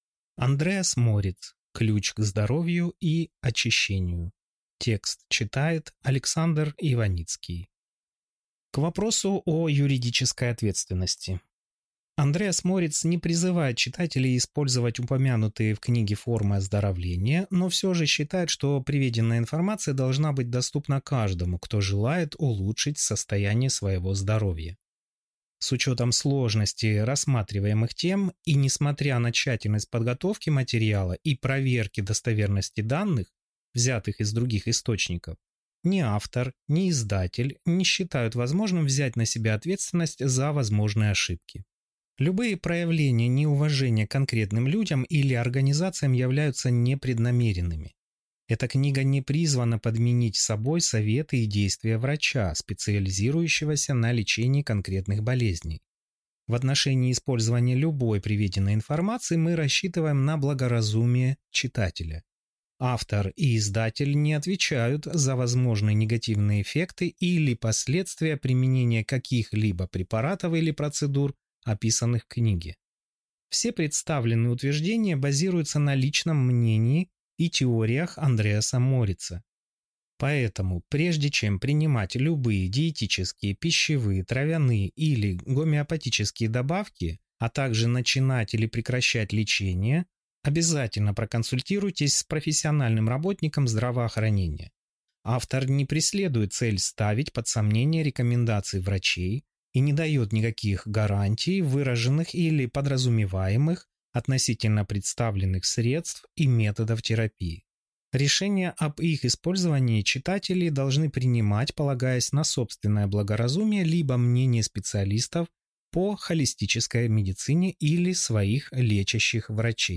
Аудиокнига Ключ к здоровью и очищению. Избавьтесь от лишнего веса и победите болезни с помощью правильного питания и эмоционального исцеления | Библиотека аудиокниг